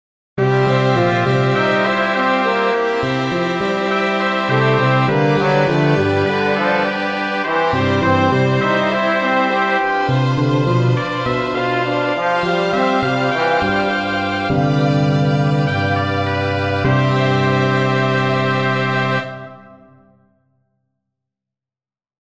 The piece is written for standard clefs and in standard 4/4 time signature, but for the card I used the first letter of the genre and for the time signature I visualized the results of a game I played with my girls.
The CDs of brass music have little fanfares and the jazz CDs are represented with a jazzy bass line.
Jazz and Pop sections together